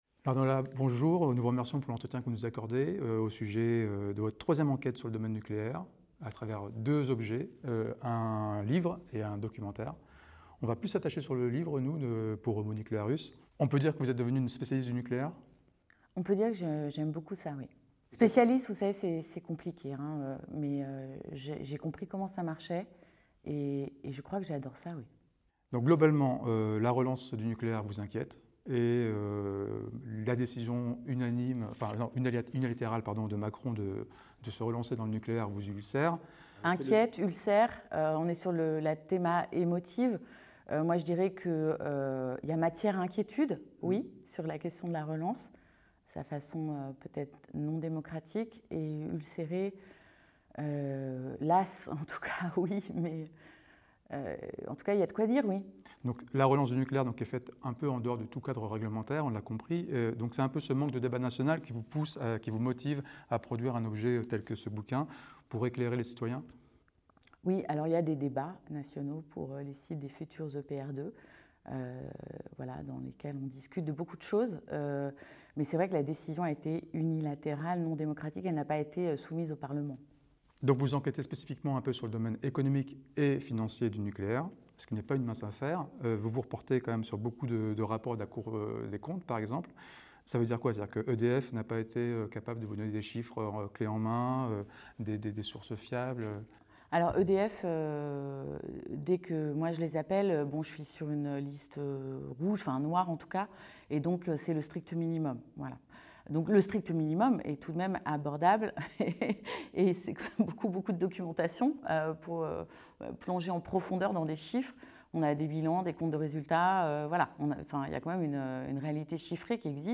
(interview